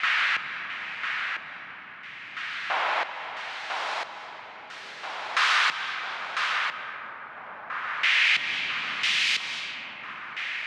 Index of /DESN275/loops/Loop Set - Spring - New Age Ambient Loops/Loops
Touchstone_90_NoiseRhythm.wav